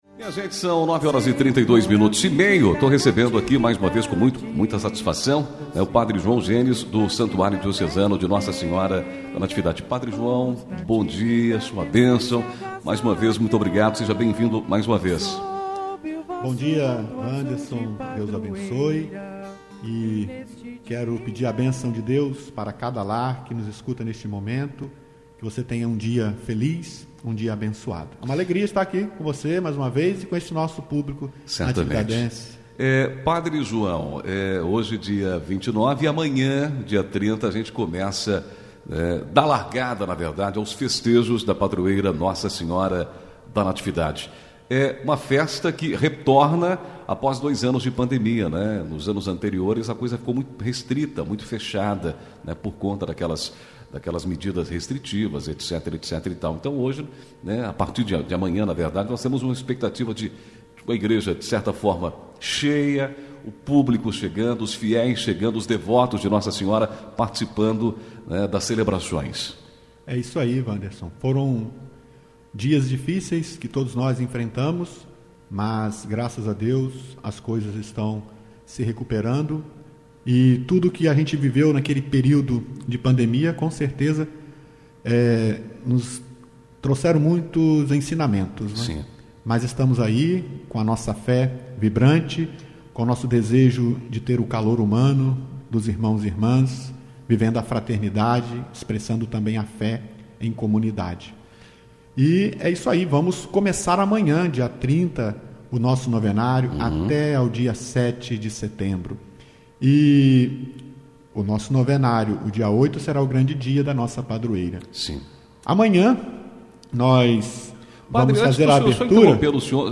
30 agosto, 2022 DESTAQUE, ENTREVISTAS